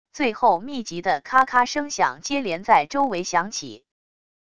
最后密集的咔咔声响接连在周围响起wav音频